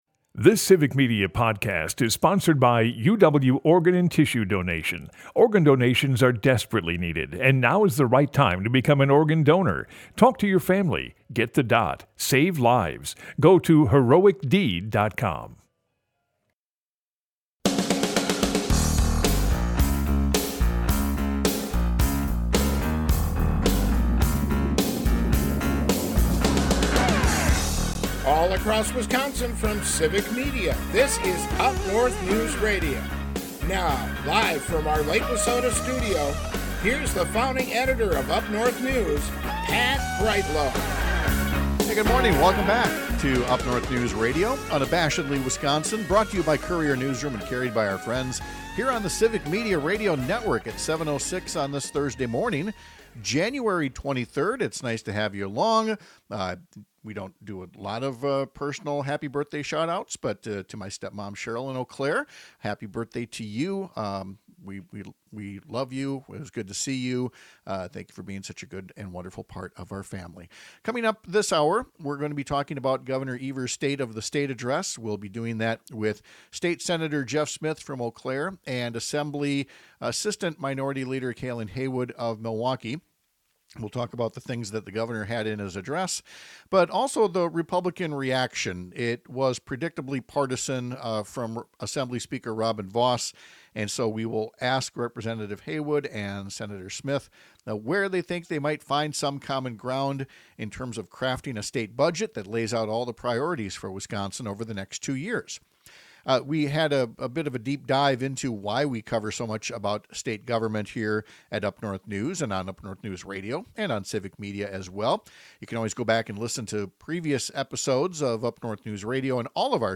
We’re joined by Assembly Assistant Minority Leader Kalan Haywood for his perspective on Gov. Tony Evers’ State of the State address and the Republican response.